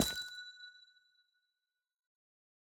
Minecraft Version Minecraft Version 1.21.5 Latest Release | Latest Snapshot 1.21.5 / assets / minecraft / sounds / block / amethyst / step7.ogg Compare With Compare With Latest Release | Latest Snapshot